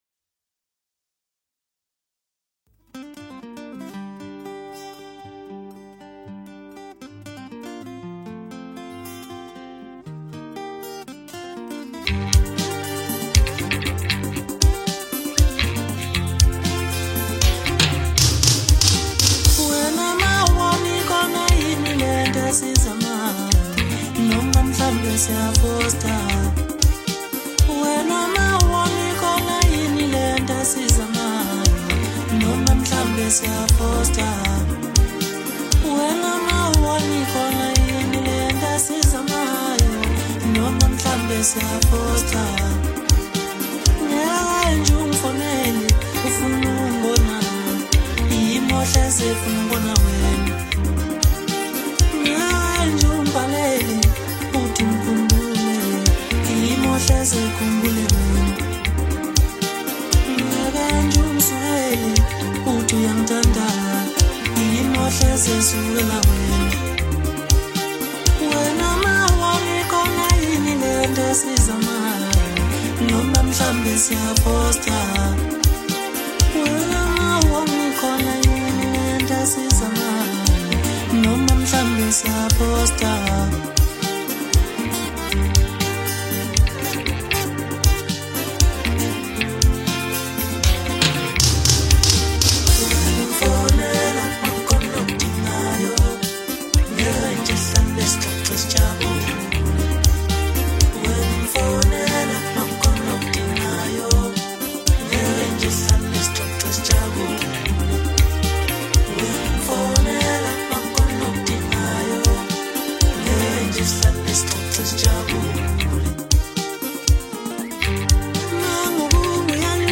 Amapiano songs